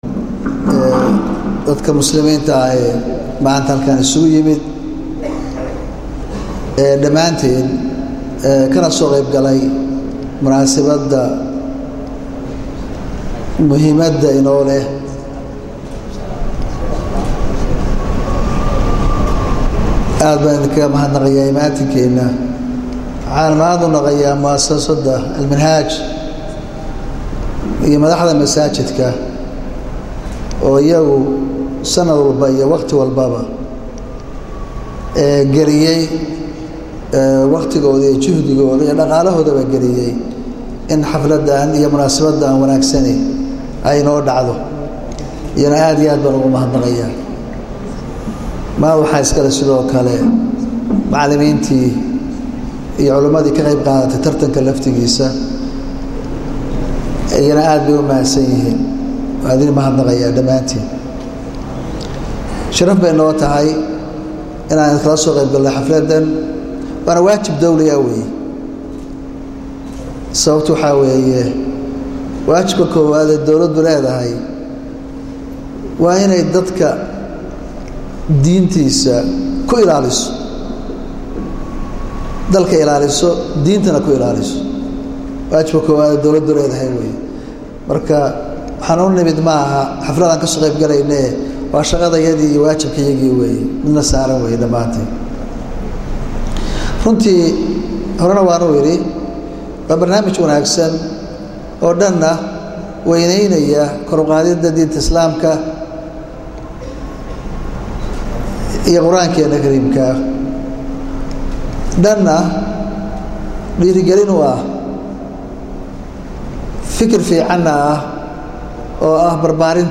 Dhagayso-Sawirro: Madaxweynaha Dawladda Puntland Garoowe kaga qayb galay Munaasabad lagu soo xirayey Tartanka Quraanka Kariimka ah
Dhagayso madaxweyne Gaas